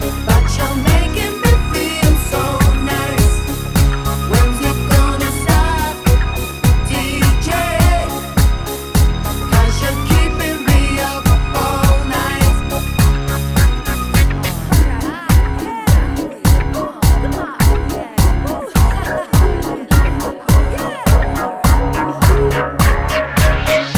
One Semitone Down Pop (2000s) 4:18 Buy £1.50